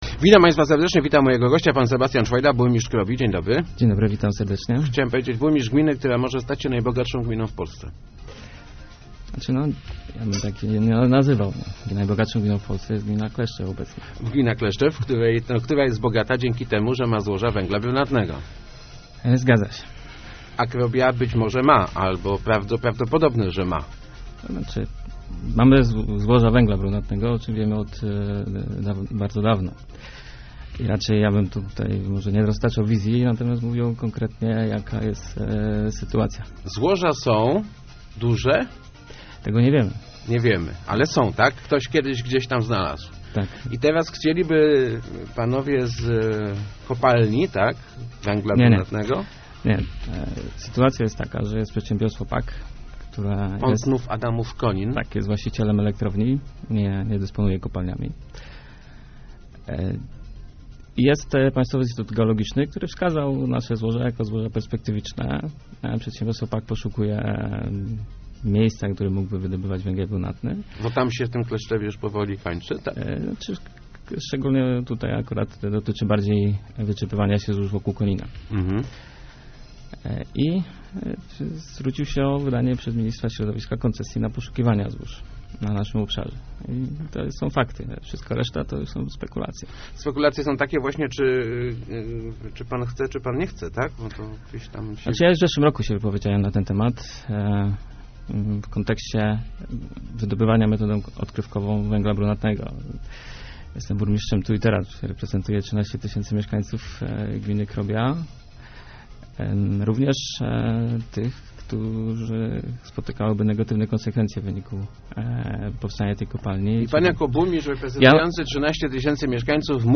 W interesie mieszkańców leży, żeby przeprowadzić te badania - mówił w Rozmowach Elki burmistrz Krobi Sebastian Czwojda, komentując sprawę poszukiwań złóż węgla brunatnego w gminie. Jak sam zastrzega nie oznacza to, że samorząd się na wydobycie zgodzi.